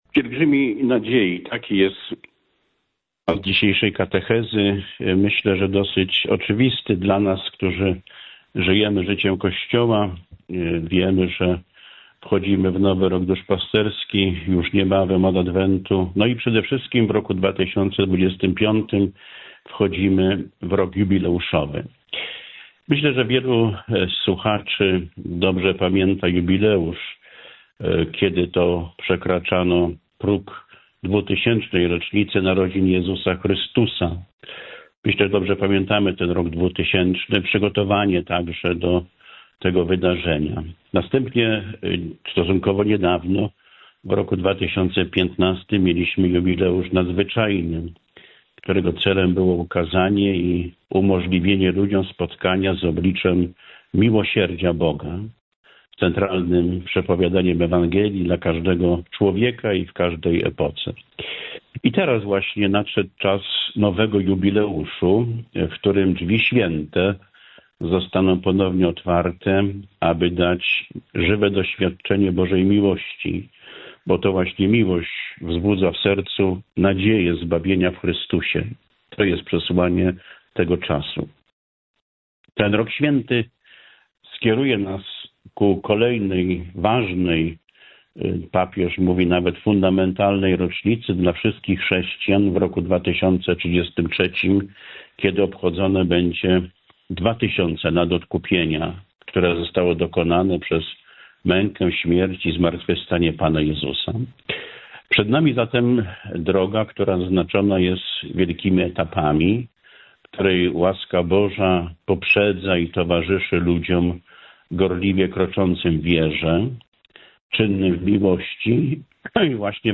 Bp Wętkowski - homilia, konferencja naukowa pro-life, WSD, 27.04.2024